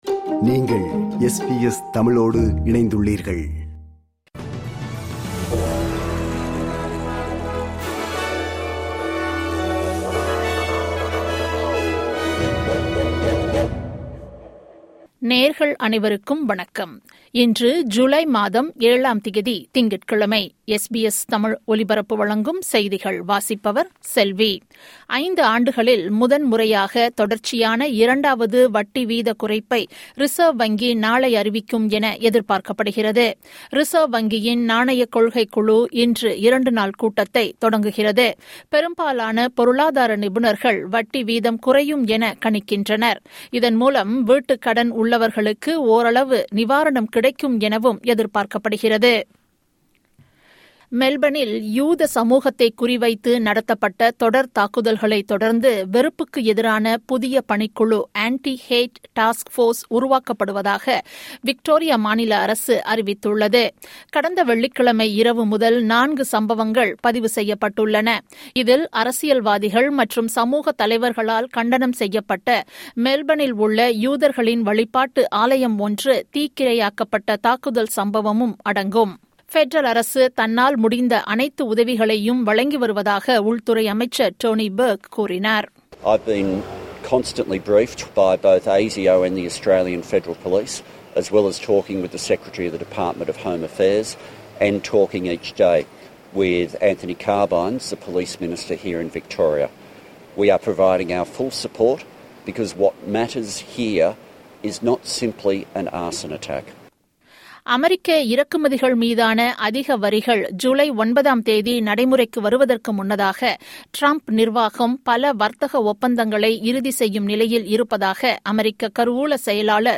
SBS தமிழ் ஒலிபரப்பின் இன்றைய (திங்கட்கிழமை 07/07/2025) செய்திகள்.